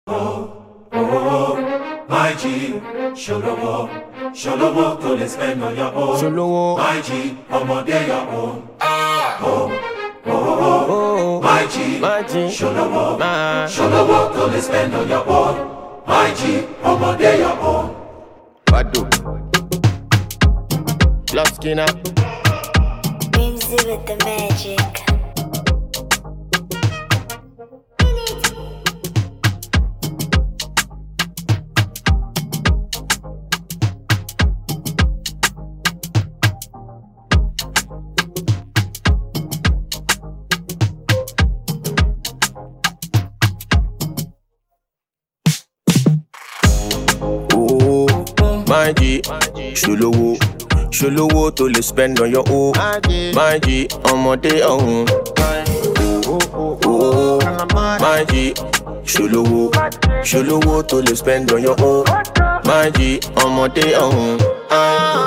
the infectious beats of Afrobeat